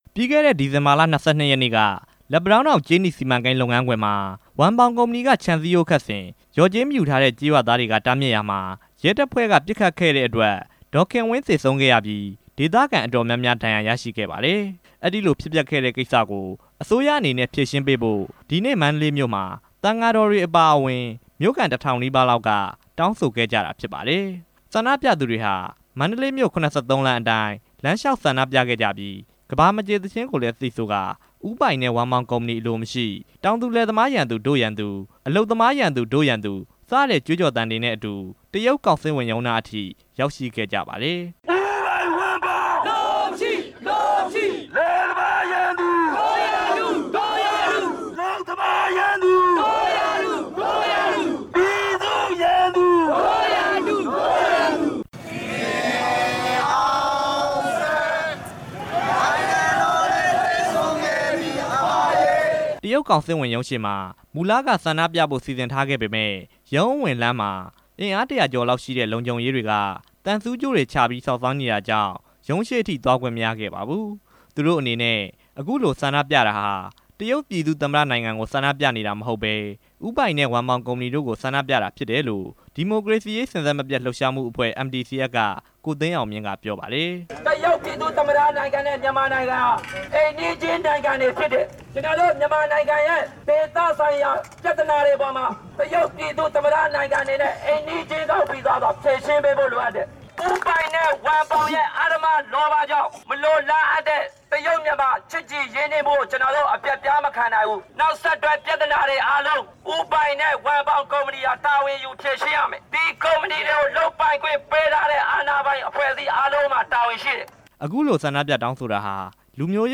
ကြေးနီစီမံကိန်း ပဋိပက္ခအတွက် မန္တလေးမြို့မှာ ဆန္ဒပြတဲ့ အကြောင်း တင်ပြချက်